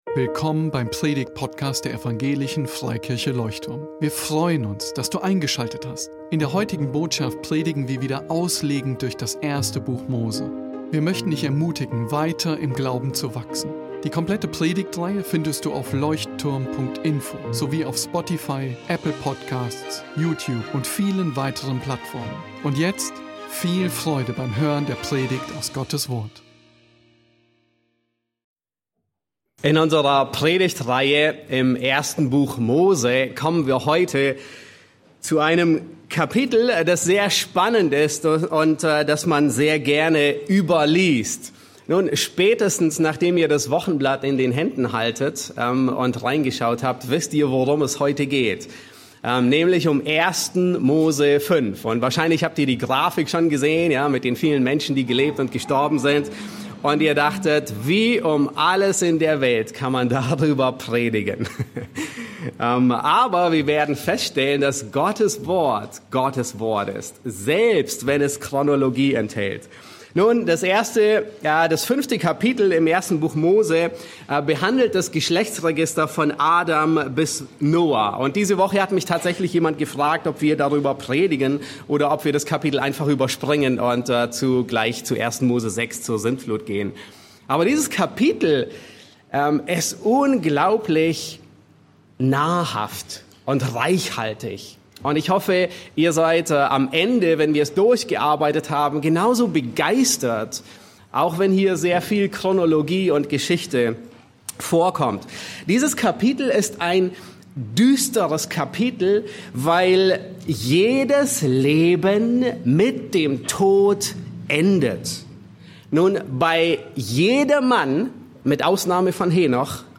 In dieser Predigt entdecken wir, wie ein scheinbar „uninteressantes“ Kapitel voller Namen und Zahlen zentrale Wahrheiten über Tod, Sünde und Hoffnung offenbart.